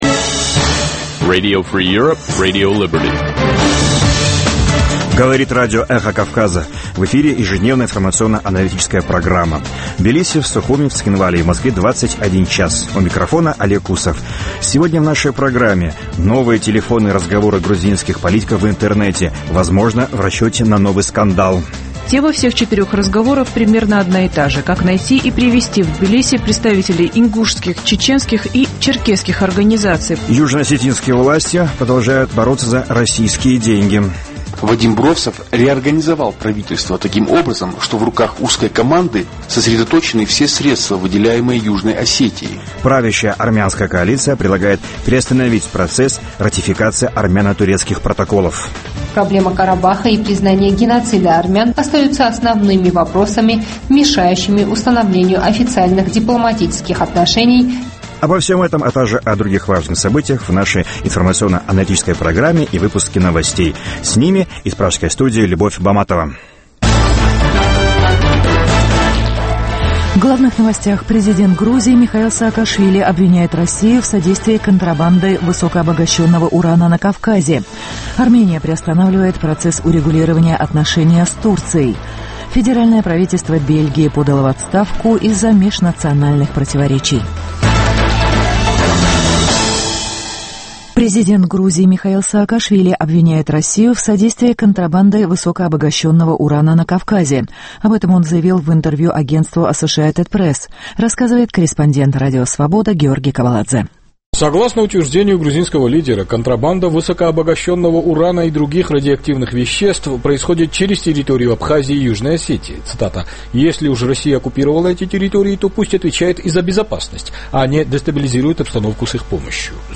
Новости, репортажи с мест, интервью с политиками и экспертами , круглые столы, социальные темы, международная жизнь, обзоры прессы, история и культура.